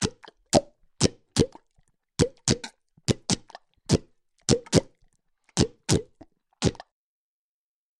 Звук отлипающих щупалец осьминога от поверхности